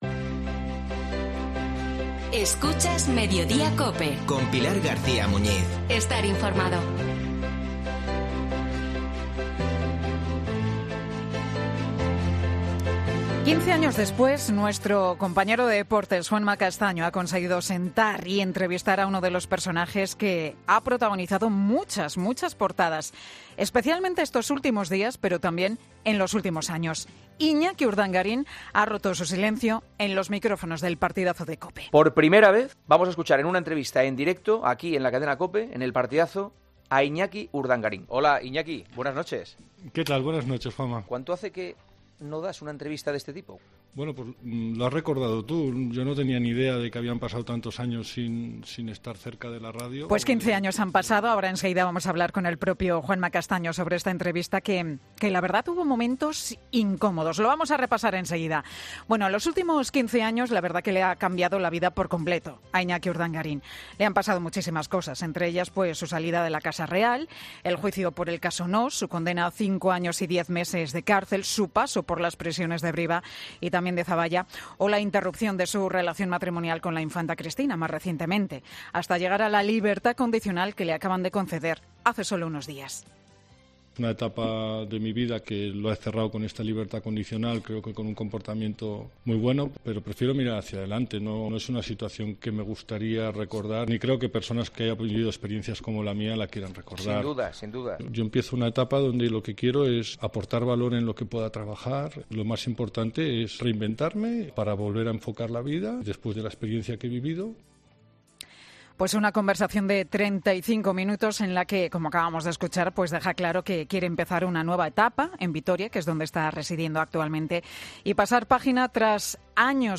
El director de El Partidazo de COPE ha contado en Mediodía COPE todos los detalles de su entrevista con Iñaki Urdangarín.